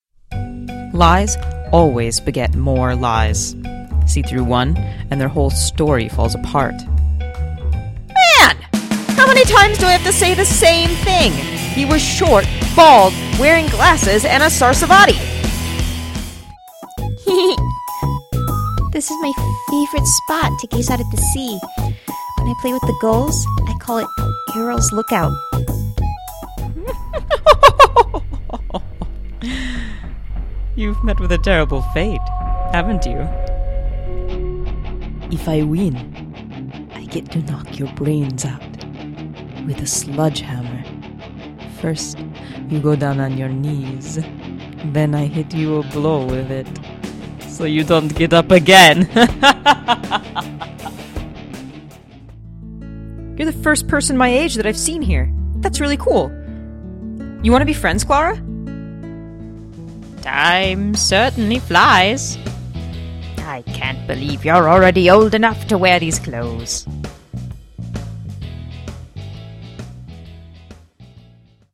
Mezzo-soprano, character actor, audiobooks, games, commercials
Sprechprobe: Sonstiges (Muttersprache):